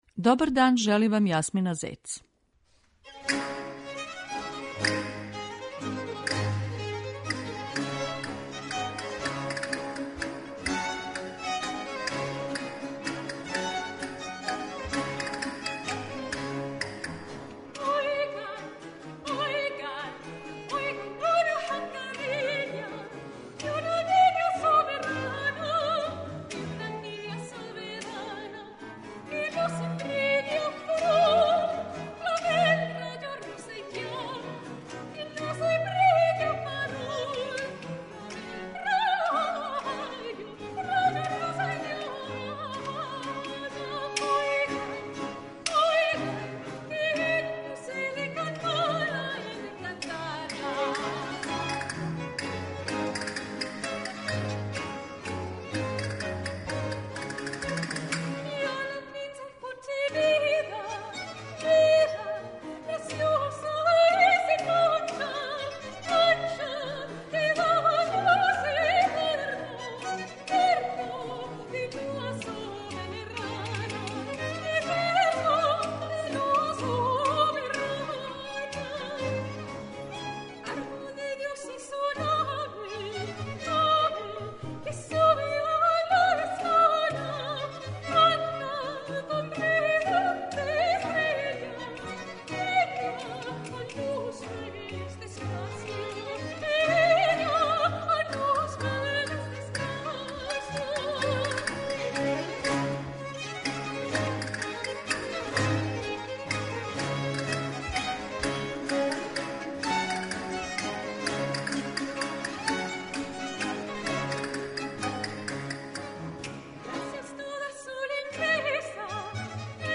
У емисији Аутограм , која је петком по традицији посвећена домаћем музичком стваралаштву, слушаћете "Симфонију лесту", нашег неокласичара Рудолфа Бручија.